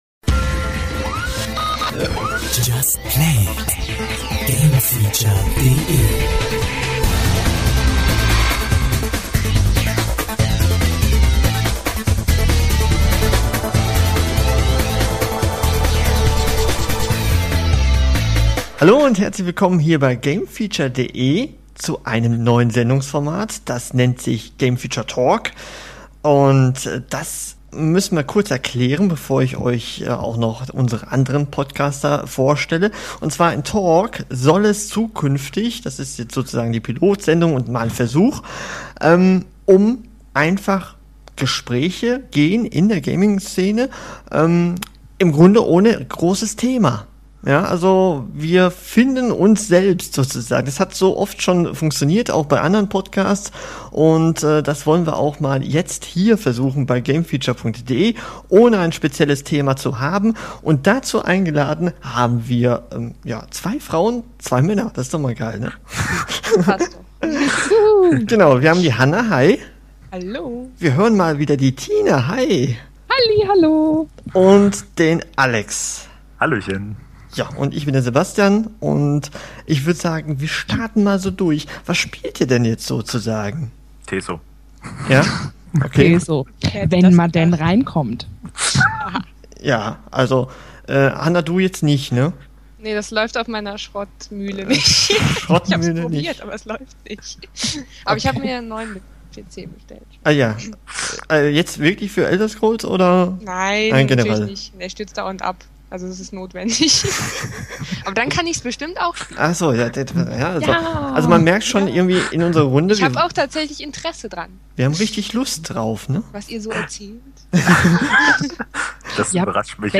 Im GameFeature Talk geht es nicht nur um Neuigkeiten aus der Gamingbranche sondern manchmal auch einfach nur um unsere ganz persönlichen Lieblingsspiele und -themen. Aber überzeugt euch am besten selbst in unserer Pilotfolge und lauscht unseren geistigen Ergüssen zu später Stunde in lockerer Runde. Es geht diesmal unter anderem um Elder Scrolls Online.